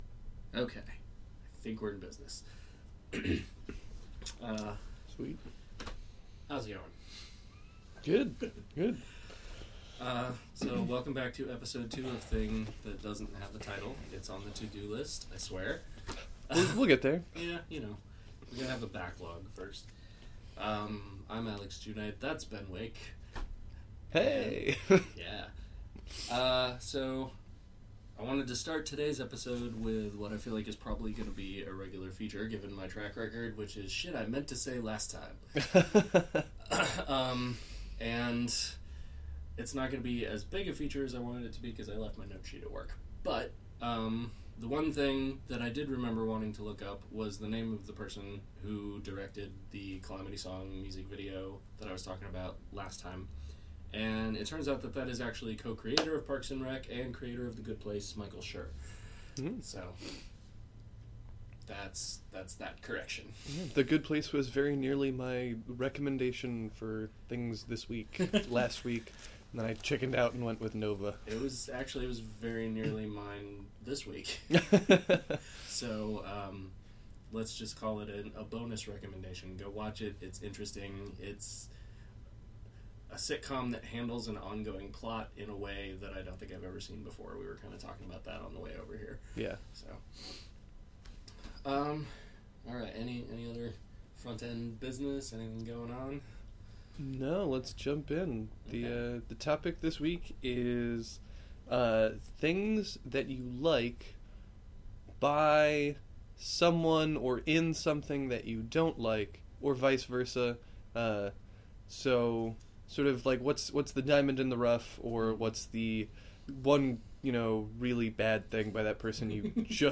A discussion of works we expected to dislike but liked or vice versa.